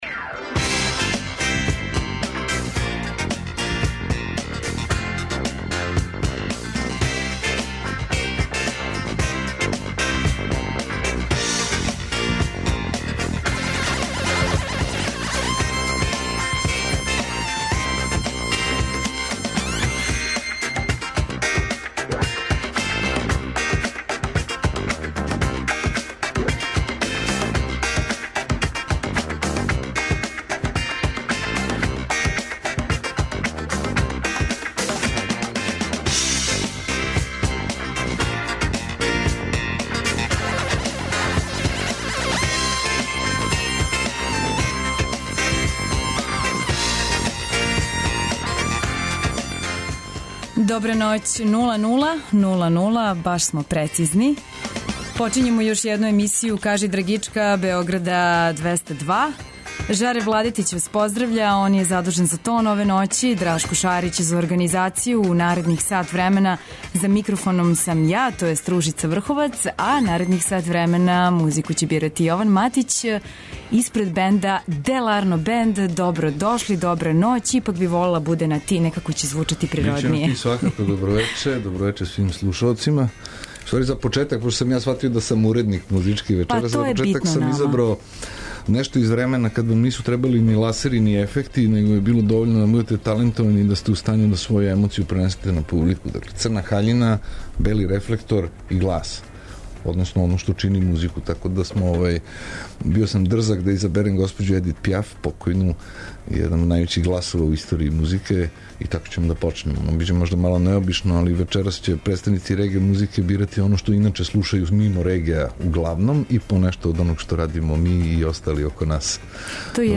Свако вече, од поноћи на Двестадвојци у емисији Кажи драгичка гост изненађења! Музички гост се, у сат времена програма, представља слушаоцима својим ауторским музичким стваралаштвом, као и музичким нумерама других аутора и извођача које су по њему значајне и које вам препоручују да чујете.